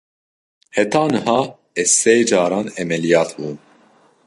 Pronunciado como (IPA)
/ʕɛmɛlɪˈjɑːt/